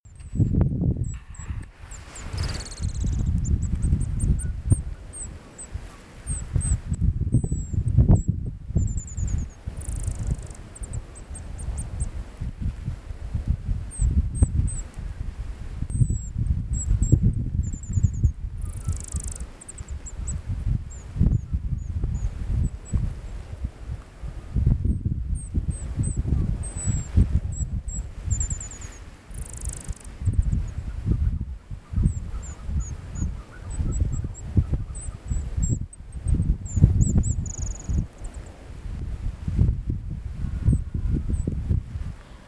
Short-tailed Grasswren
Mount Scott, Mount Ive Station, Gawler Ranges, South Australia, Australia
Amytornis merrotsyi pedleri
Grasswren Short-tailed (pedleri) MT SCOTT SA AUS song [A] ETSJ_LS_71233.mp3